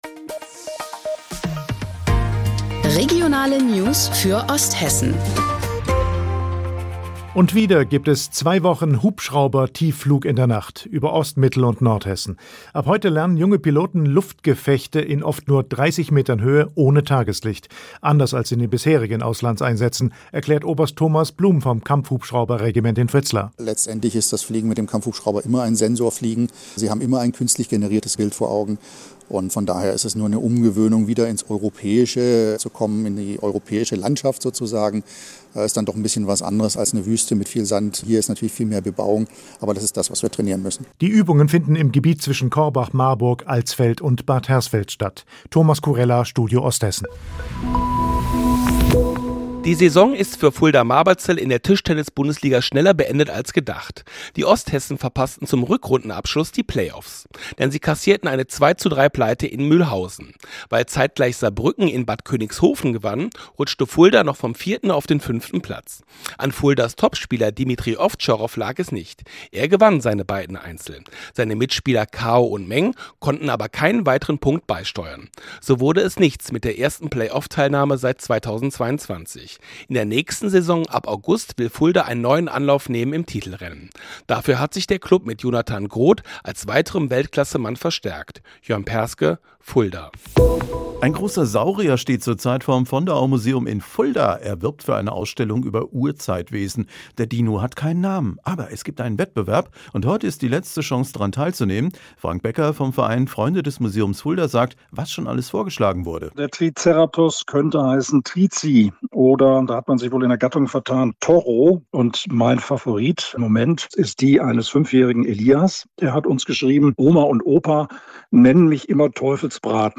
Mittags eine aktuelle Reportage des Studios Fulda für die Region